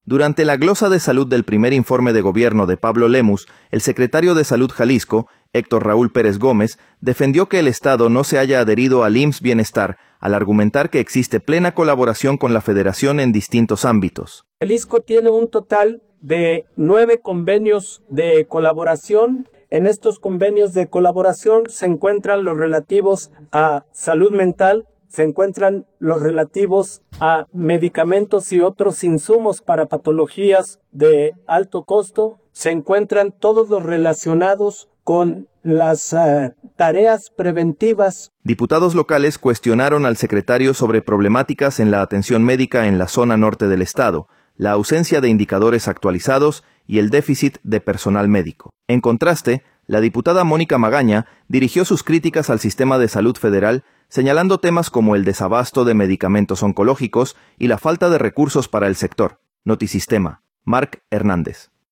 Durante la glosa de salud del primer informe de Gobierno de Pablo Lemus, el secretario de Salud Jalisco, Héctor Raúl Pérez Gómez, defendió que el estado no se haya adherido al IMSS-Bienestar, al argumentar que existe plena colaboración con la federación en distintos ámbitos.